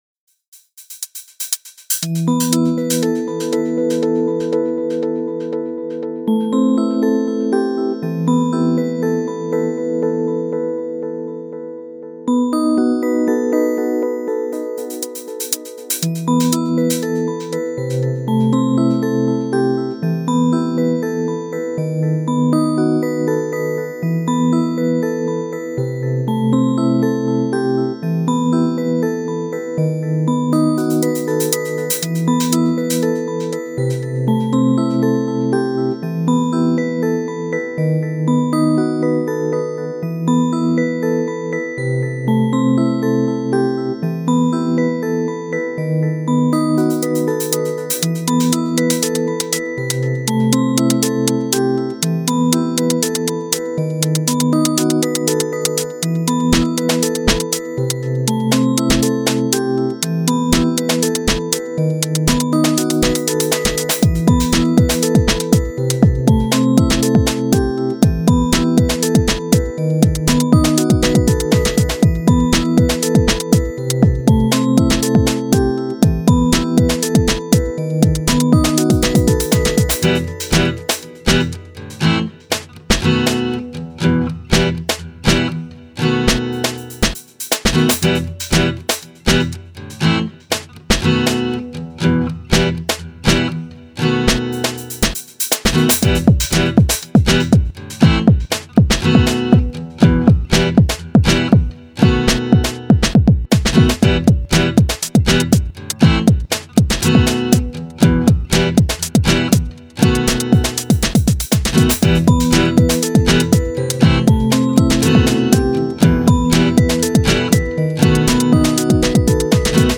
You can listen to the 120bpm
guitar